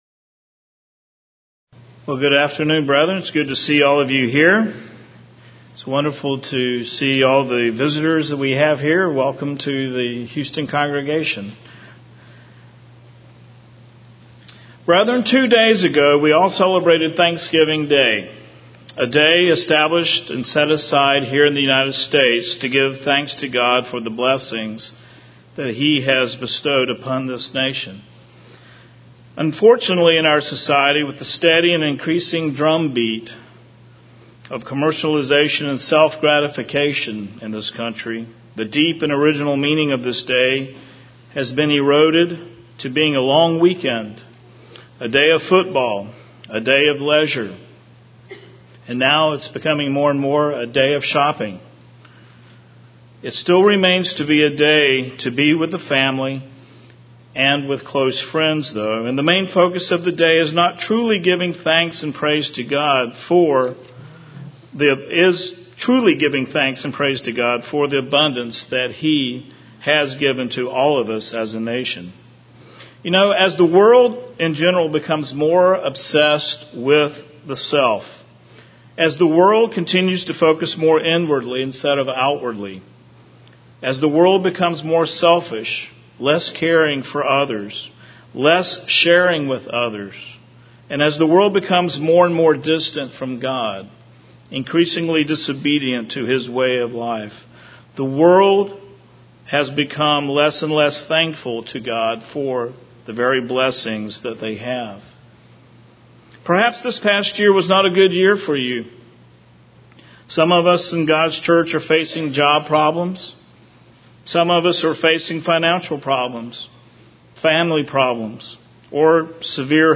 Sermon
Given in Houston, TX